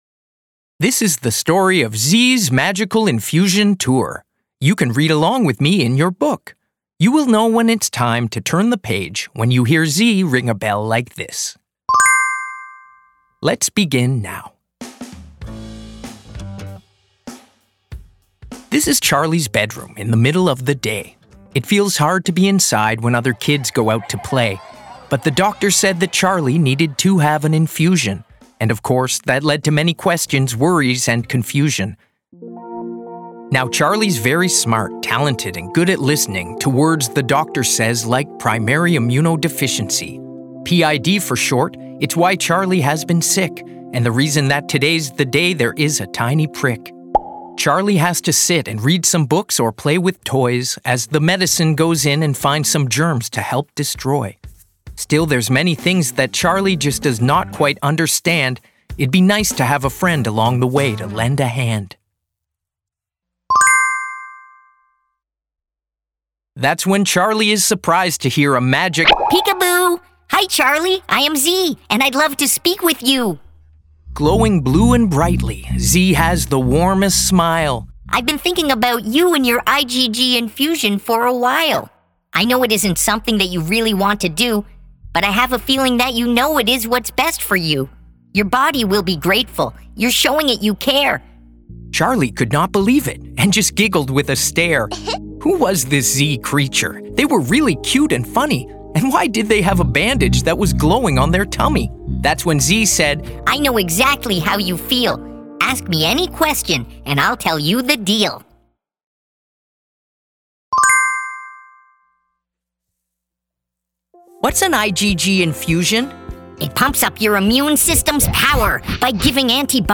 AUDIO BOOK
Hizentra-Audiobook-EN.mp3